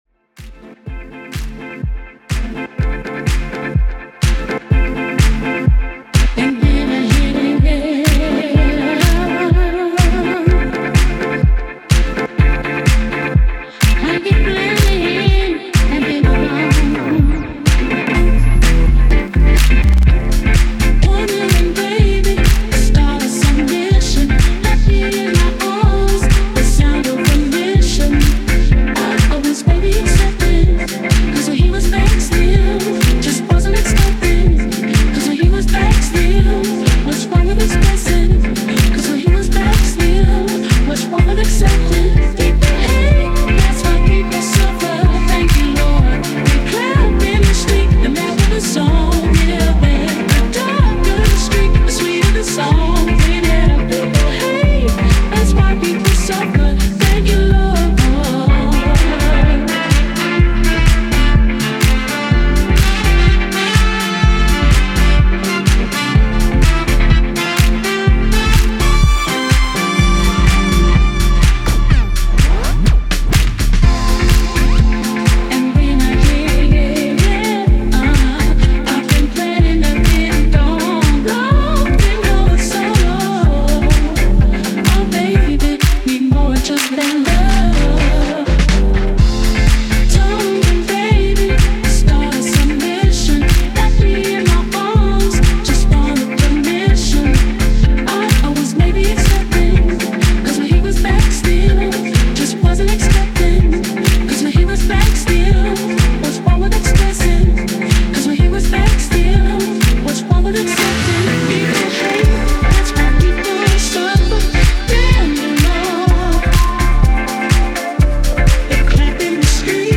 As always, limited and LOUD